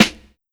Light Snare.wav